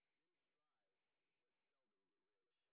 sp06_train_snr10.wav